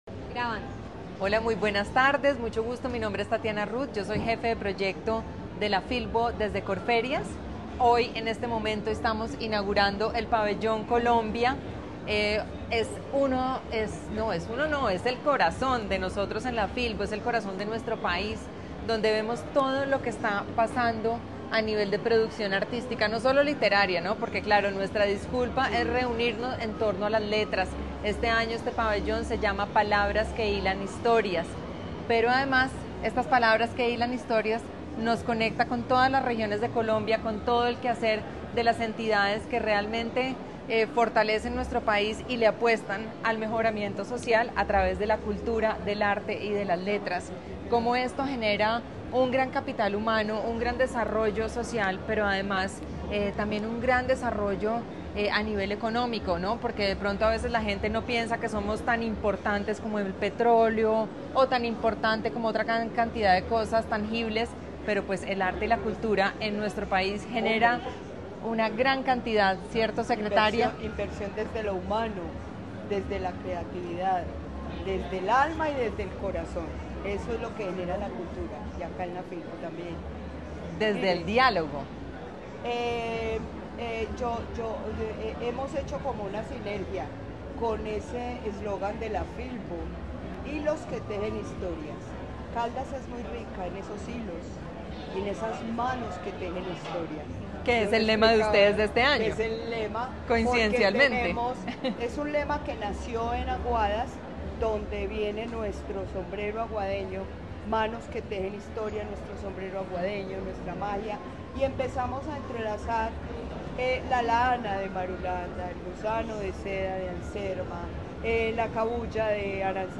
/ Secretaria de Cultura de Caldas, Luz Elena Castaño Rendón.